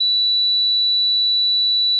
Hoge toon (4000 Hz)
high_tone.wav